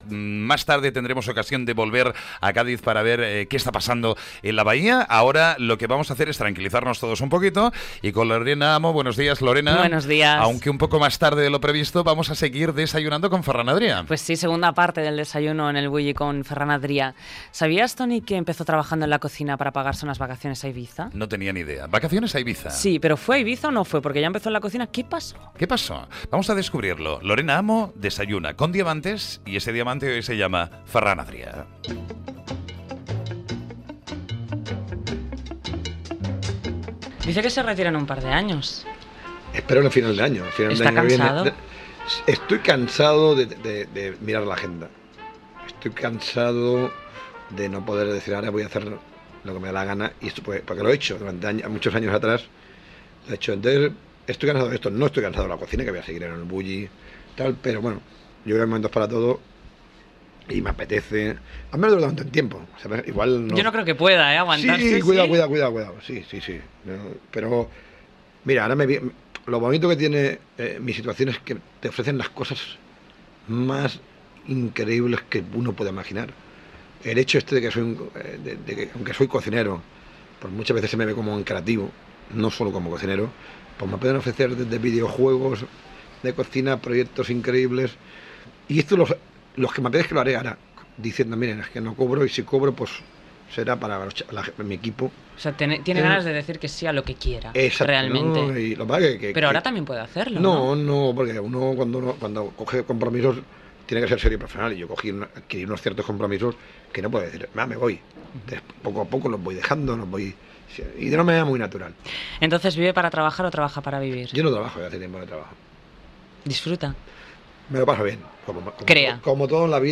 Secció "Desayuno con diamantes" amb una entrevista a Ferran Adrià, feta al seu restaurant El Bulli
Entreteniment